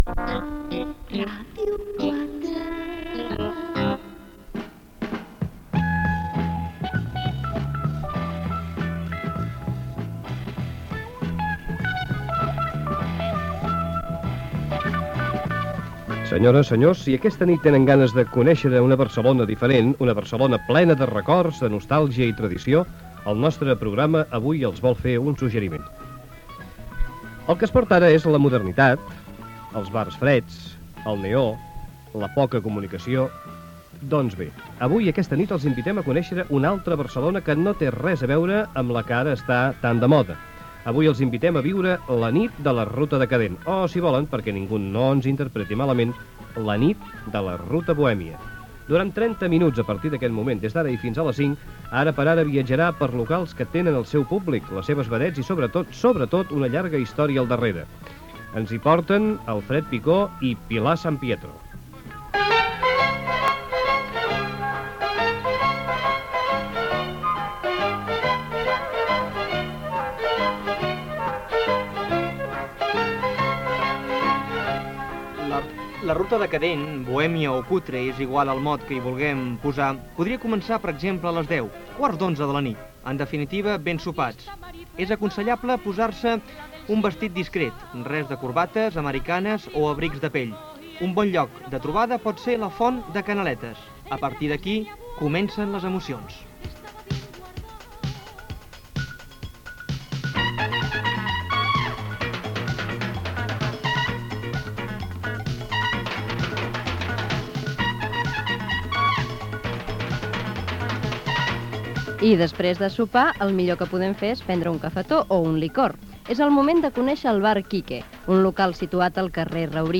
Indicatiu de la ràdio, reportatge sobre la ruta decadent o bohèmia de Barcelona